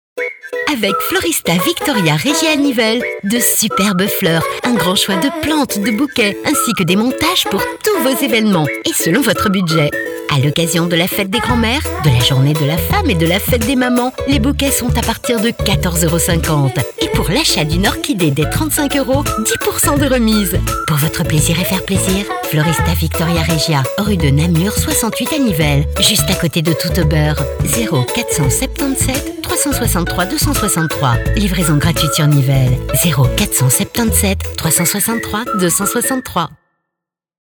Voix off
PUB
voixmediumgrave
sensuelle